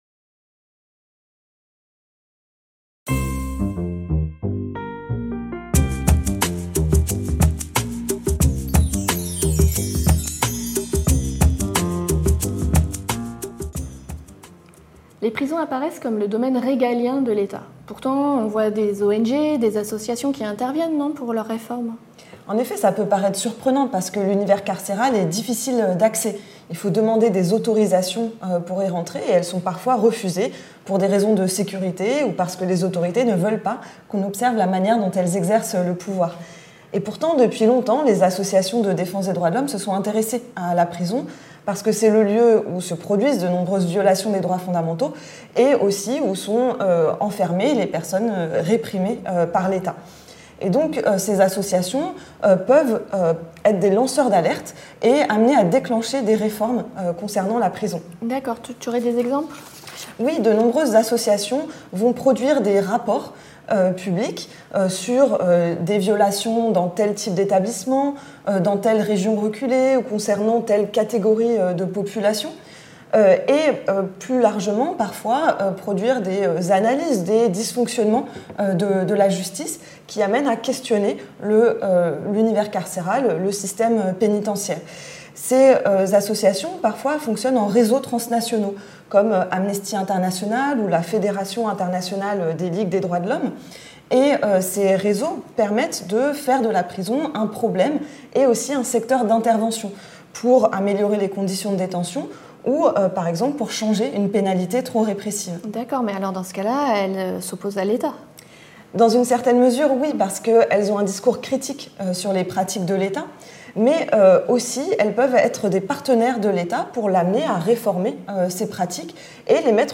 Si la prison apparaît comme le domaine régalien de l’État, cette séquence montre que les associations, ONG et plus largement la société civile jouent un rôle important, à la fois comme lanceurs d’alerte pour dénoncer d’éventuelles violations des droits des prisonniers, mais aussi comme partenaires pour améliorer les conditions de détention. Le témoignage d’une responsable d’ONG sud-africaine nous apprend ainsi comment la société civile peut être sollicitée pour apporter des solutions aux violences en prison.